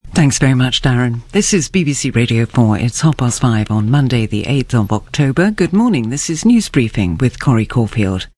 More vocoder examples
Vocoded speech, carrier pitched up more but less formant shift.  Again sounds more child like, or maybe chipmunk like!